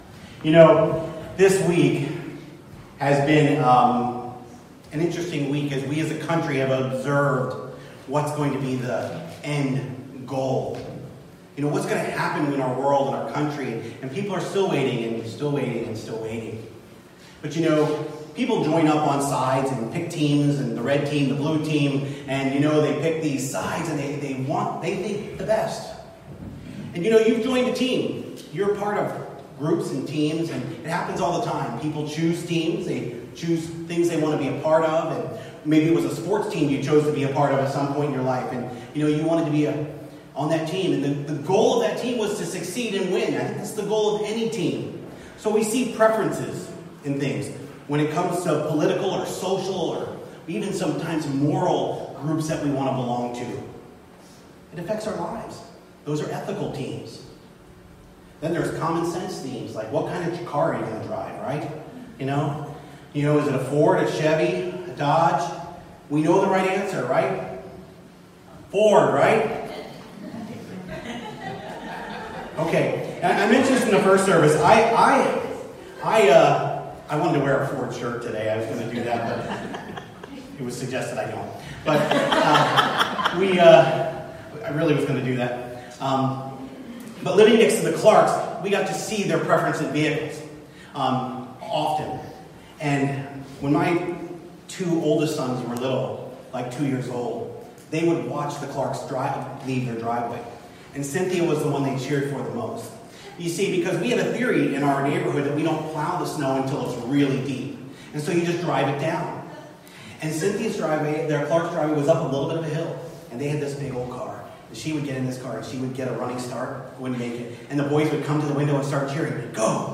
Nov 8, 2020 | by Guest Speaker | series: Miscellaneous